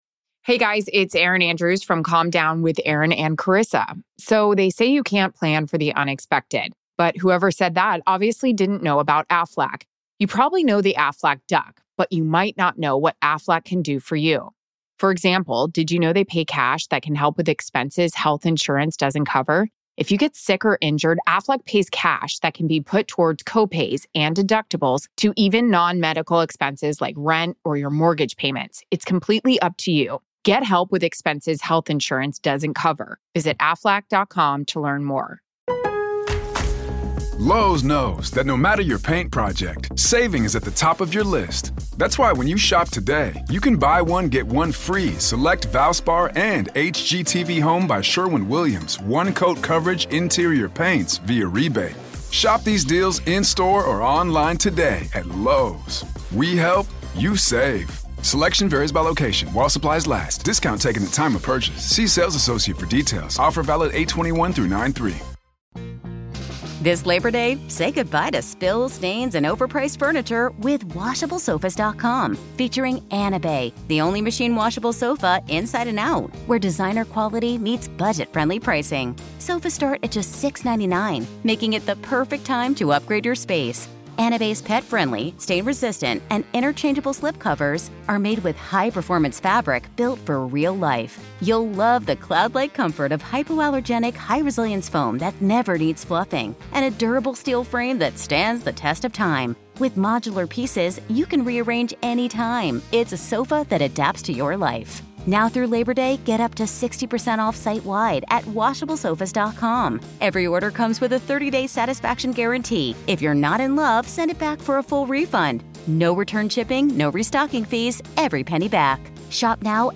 a conversation about the Haunted Rock Island Roadhouse